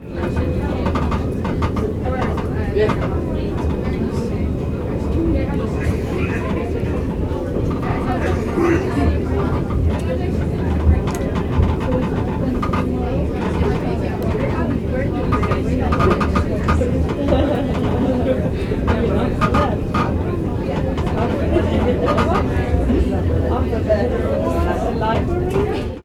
transport
Tram Cabin Inside 4